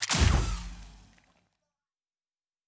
pgs/Assets/Audio/Sci-Fi Sounds/Weapons/Weapon 09 Shoot 1.wav at master
Weapon 09 Shoot 1.wav